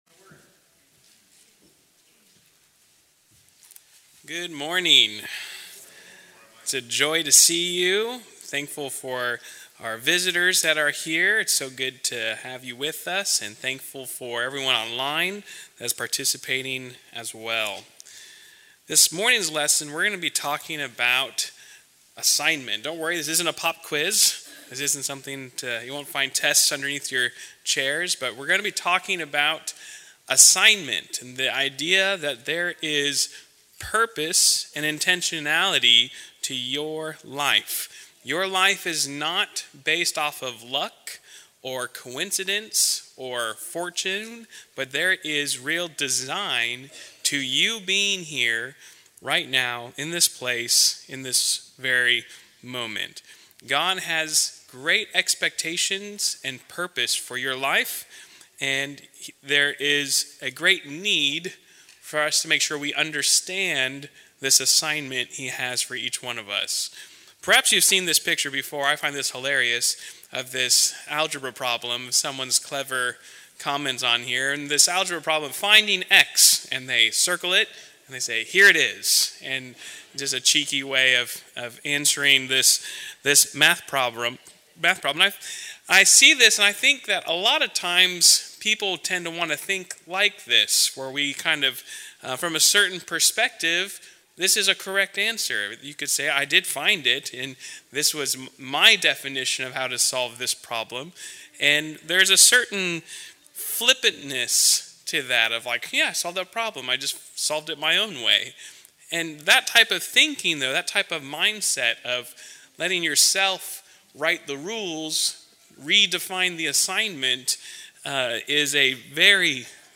Valley church of Christ - Matanuska-Susitna Valley Alaska
Audio Sermons